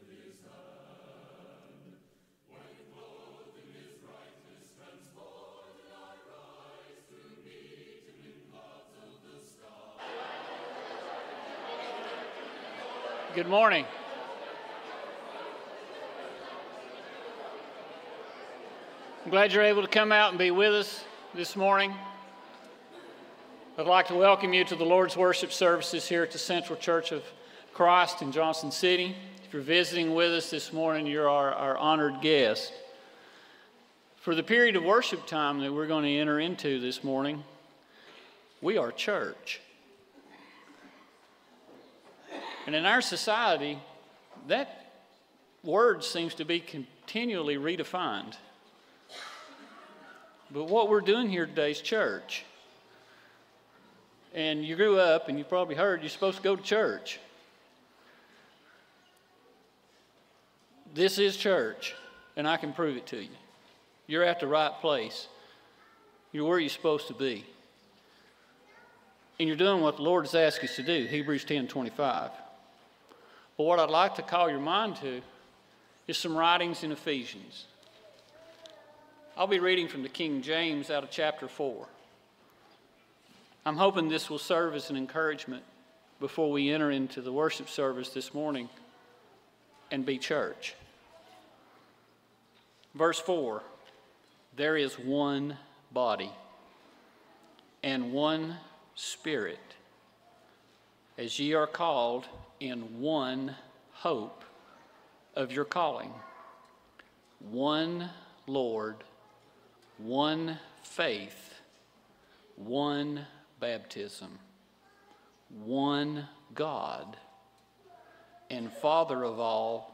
John 8:32, English Standard Version Series: Sunday AM Service